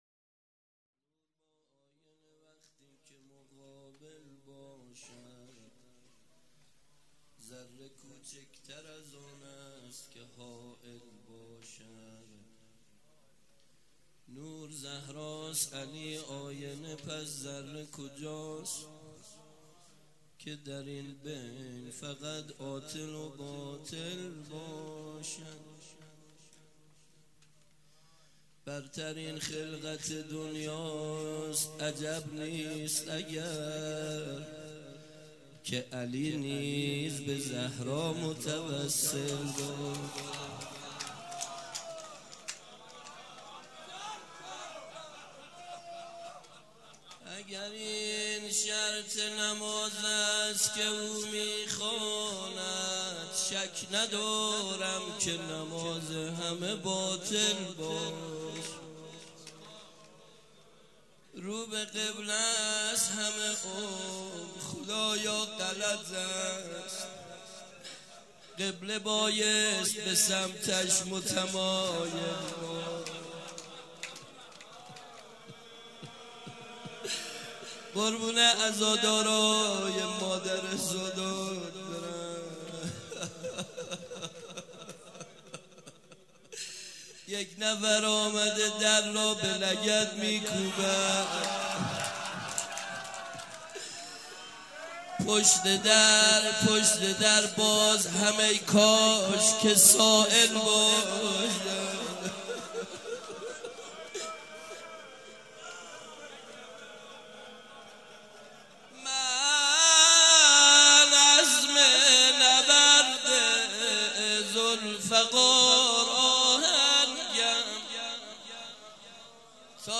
مراسم شب اول دهه فاطمیه دوم
شعر خوانی
مراسم عزاداری شب اول